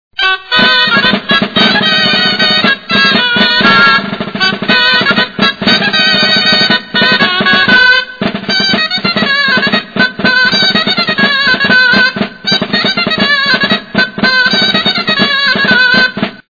Música del Ball de CintesBall de Cintes (de les Presses, a la Vall d'Hostoles)